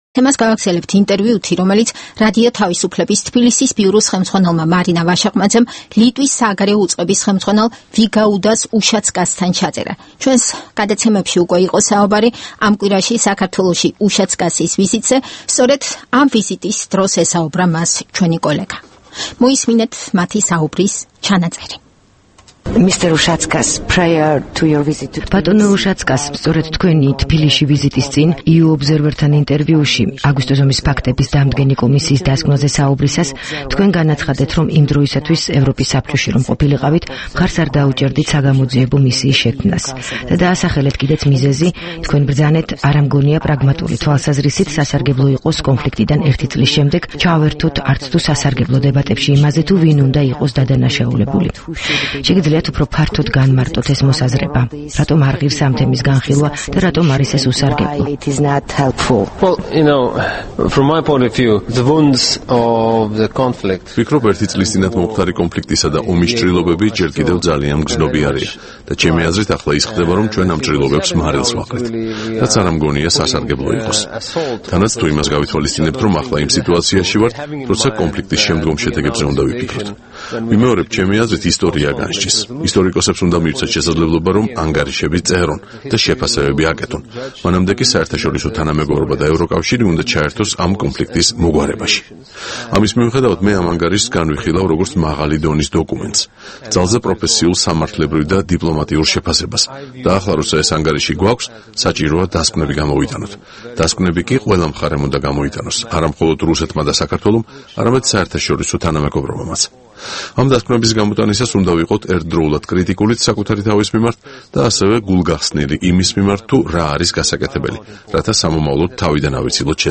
ინტერვიუ ლიტვის საგარეო საქმეთა მინისტრთან